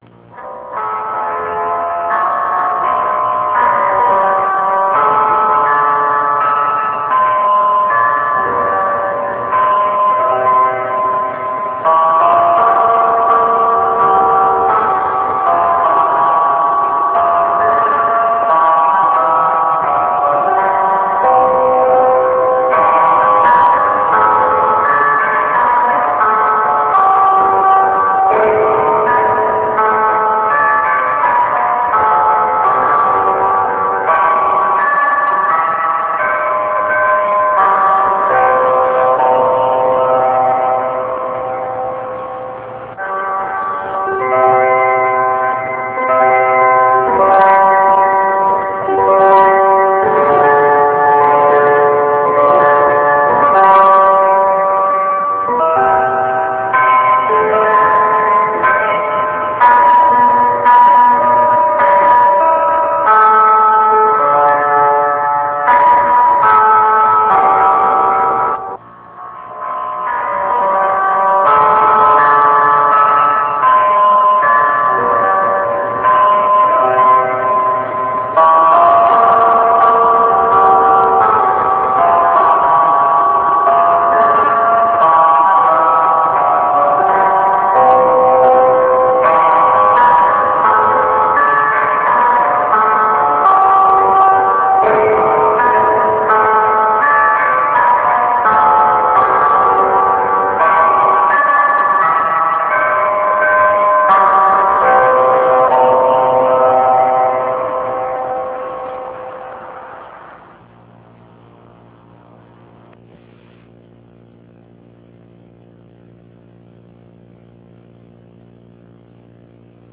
Video of Traditional Latin Mass and lecture on its history